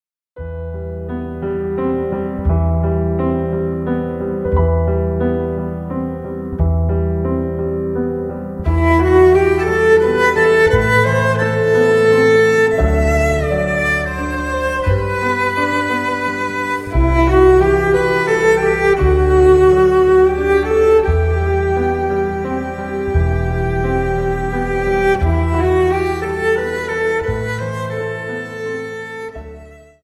Slow Waltz 29 Song